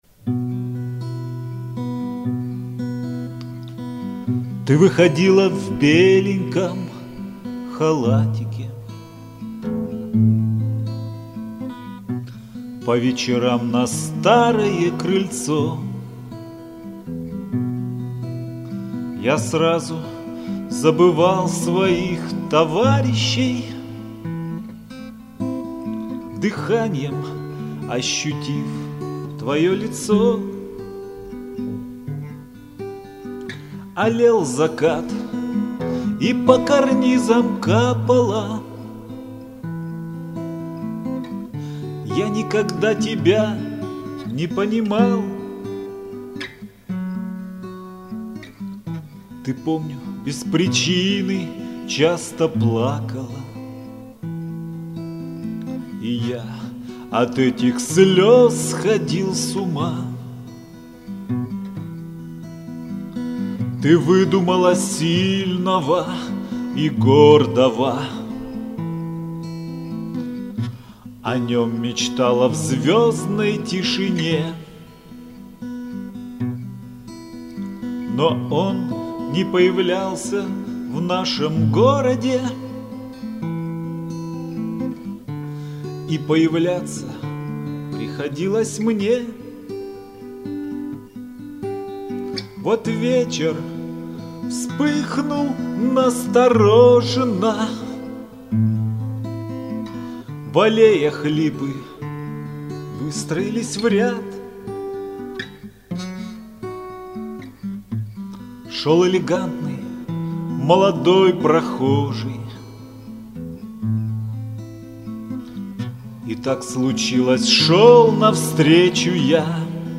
Армейские и дворовые песни под гитару
Теги: Дворовые, любовь